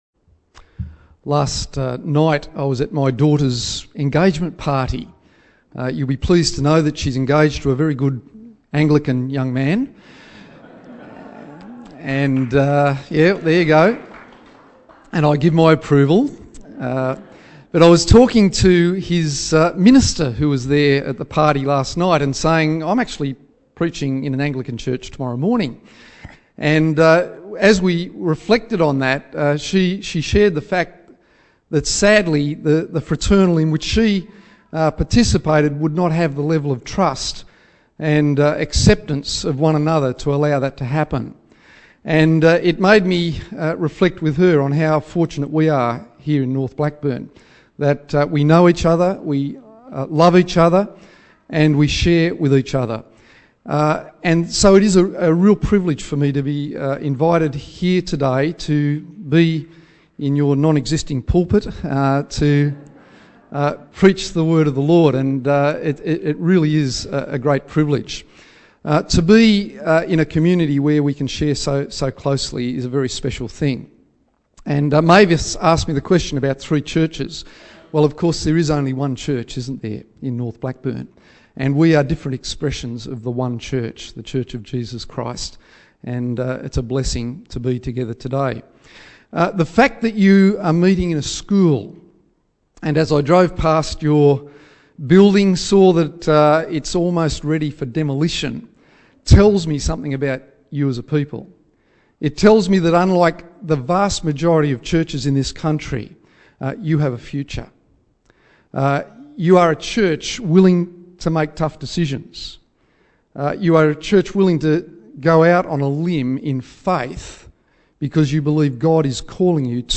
' as part of the series 'Standalone Sermon'.